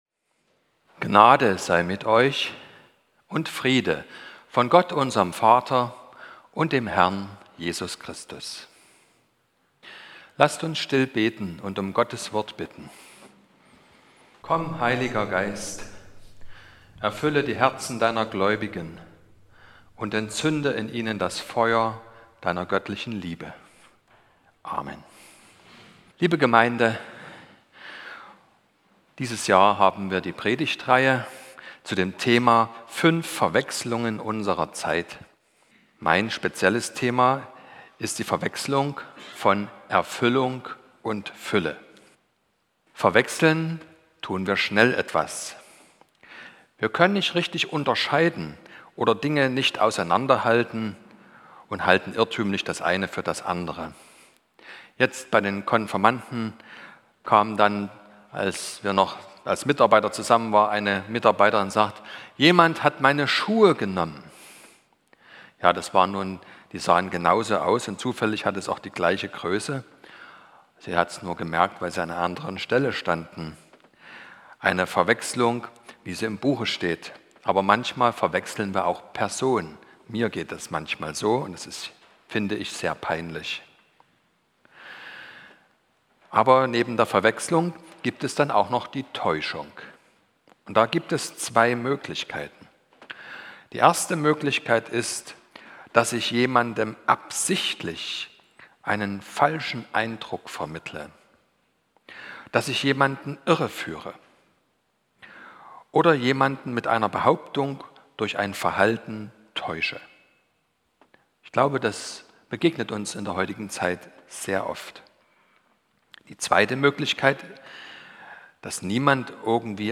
22.01.2023 – Gottesdienst
Predigt und Aufzeichnungen